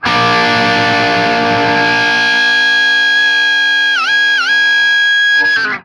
TRIAD A#  -R.wav